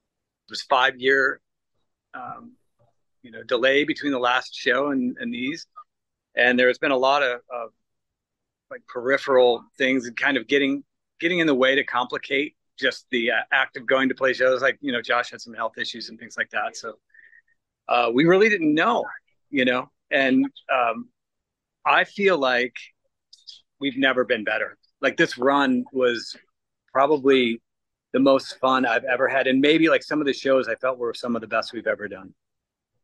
Guitarist-keyboardist Dean Fertita tells us that the band leader Josh Homme and the rest of the quintet are coming home with plenty of momentum following the shows in Europe: